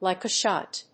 lìke a shót